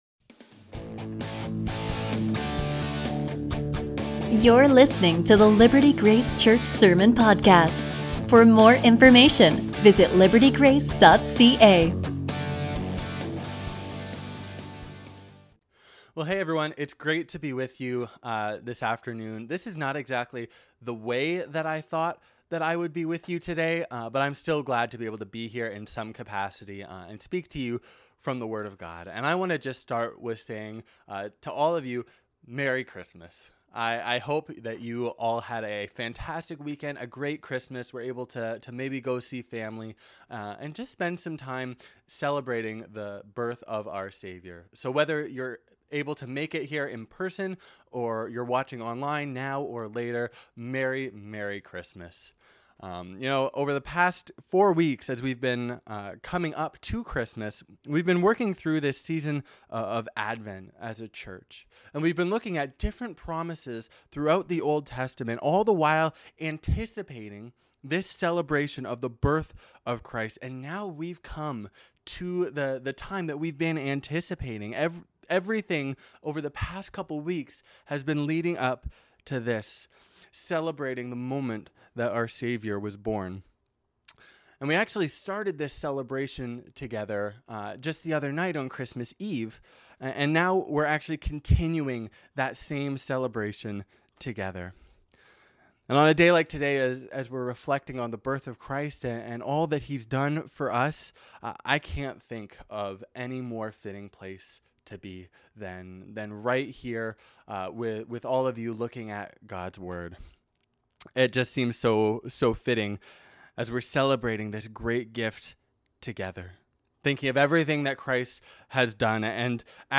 A message from the series "A King Will Come."